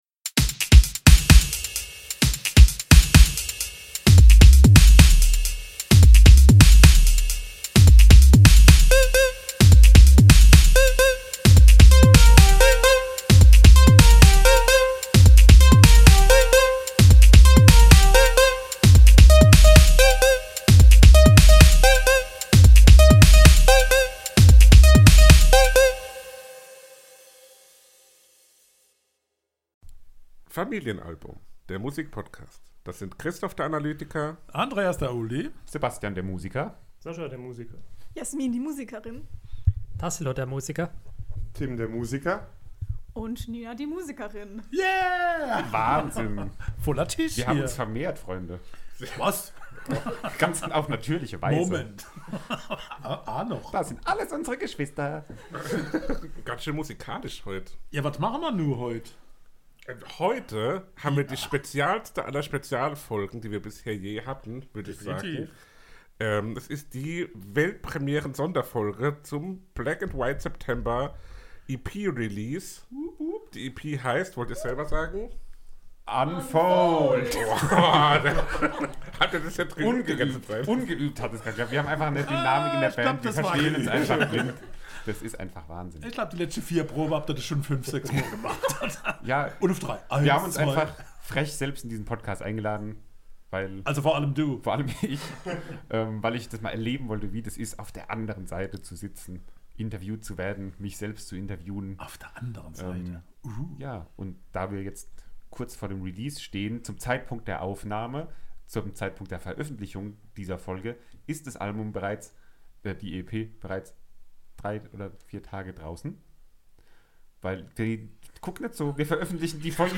Eine Ganze Band sitzt mit uns am Tisch und spricht über die ihre erste EP!
interview-mit-black-white-september-unfold-ep-mmp.mp3